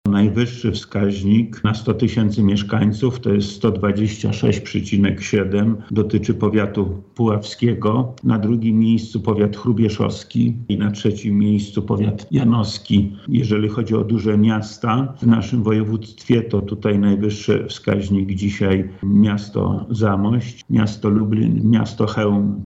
O dokładniejszych statystykach mówi Wojewoda Lubelski Lech Sprawka: